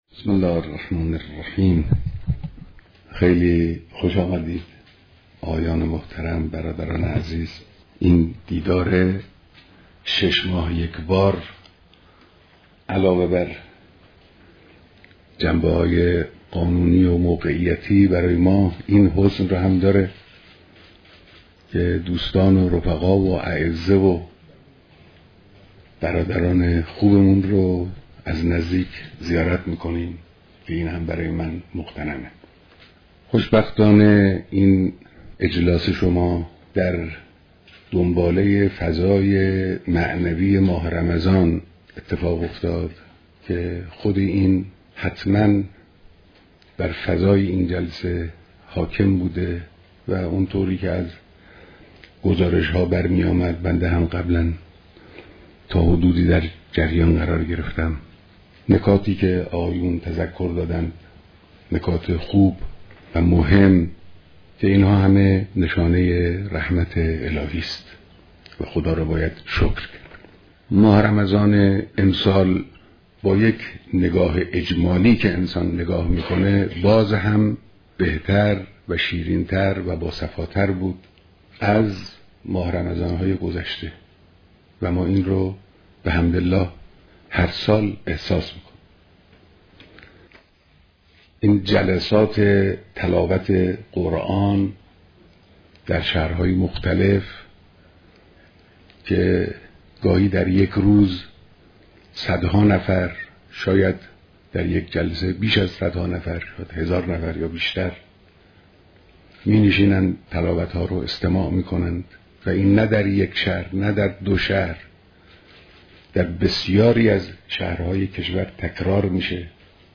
بيانات در ديدار اعضاى مجلس خبرگان رهبرى‌